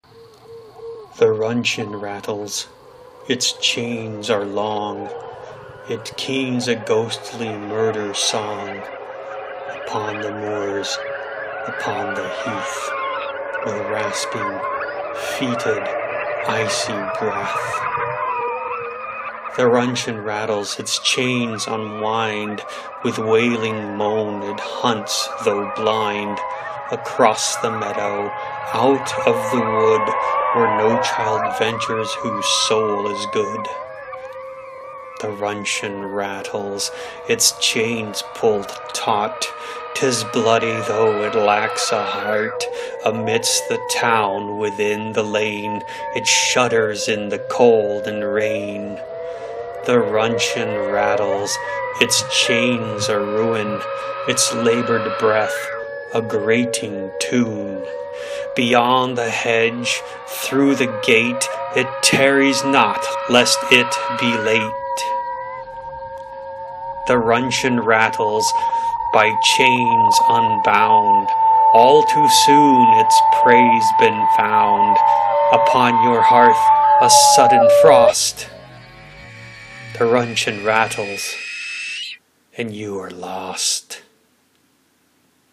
1. Record yourself reading one of your own works.